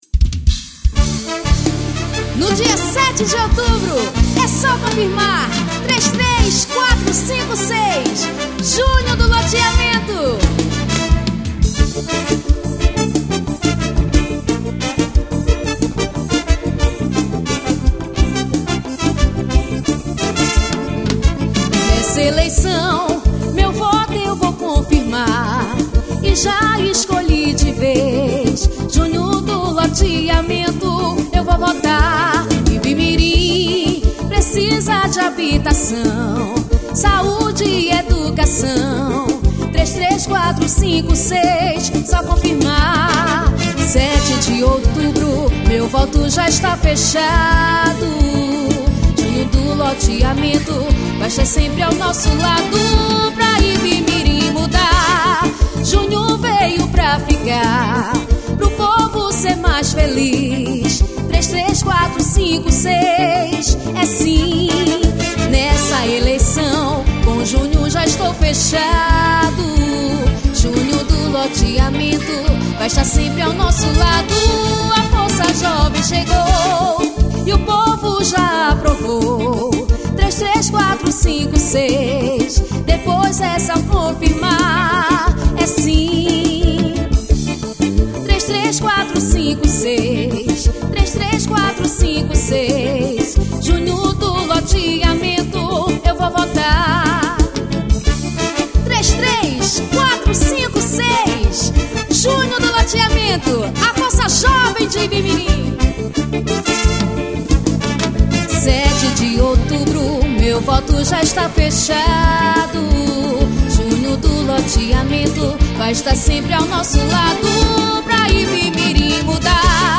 Paródias Políticas.